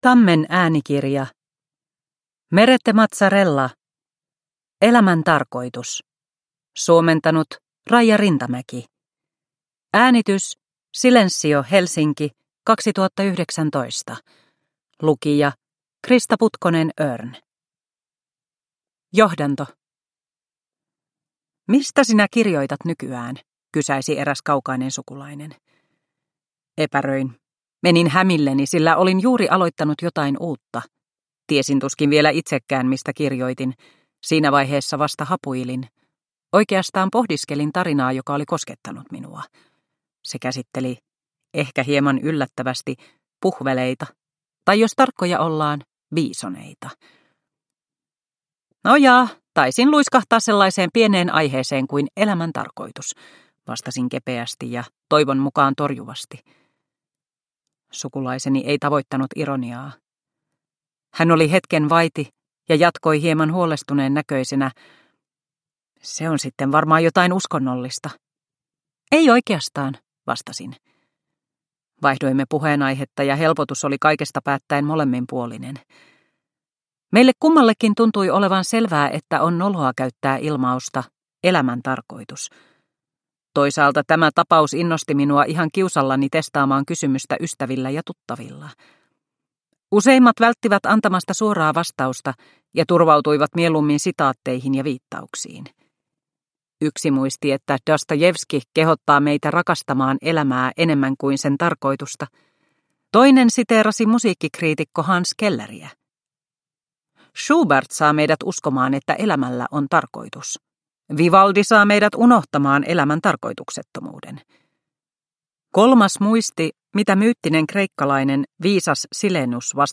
Elämän tarkoitus – Ljudbok – Laddas ner